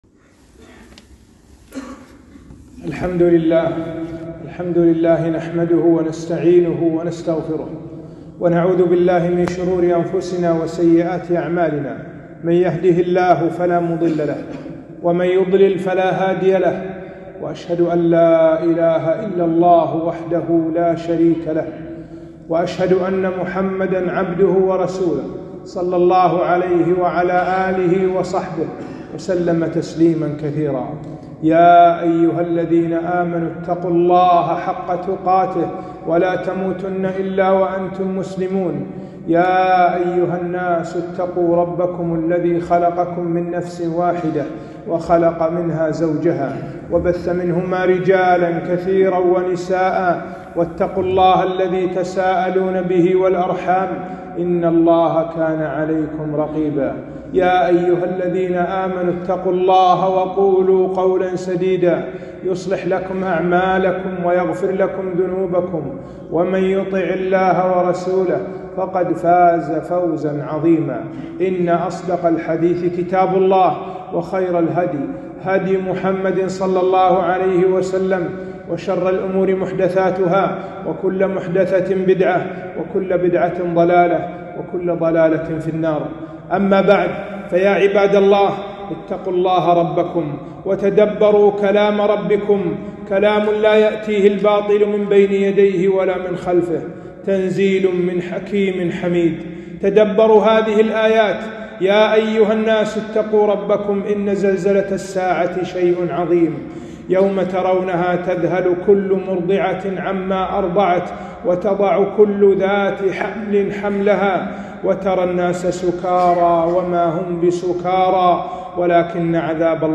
خطبة - الأمر بالمعروف والنهي عن المنكر